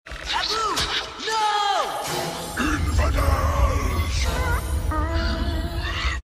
Play, download and share Abu steals gem original sound button!!!!
diamond_medium.mp3